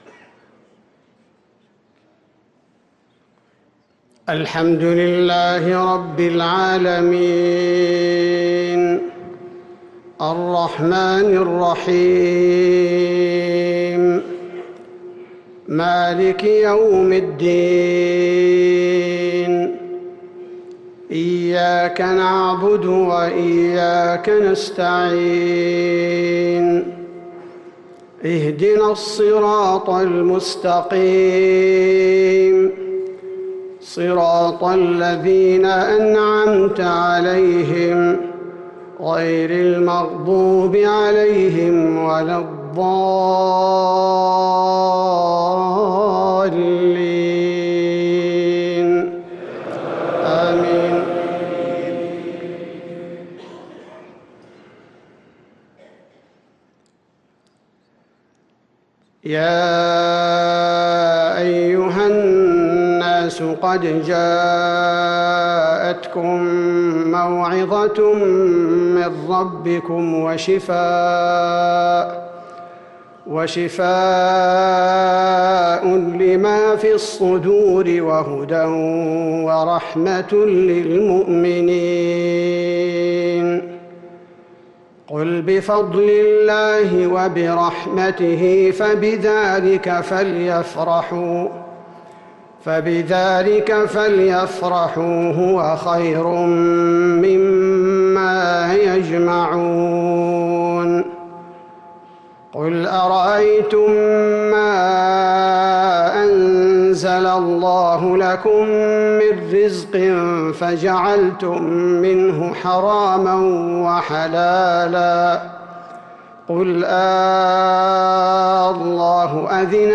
صلاة العشاء للقارئ عبدالباري الثبيتي 29 شعبان 1444 هـ
تِلَاوَات الْحَرَمَيْن .